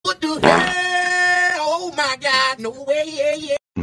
What The Hell Fart Sound Button - Free Download & Play